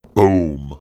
big_boom.ogg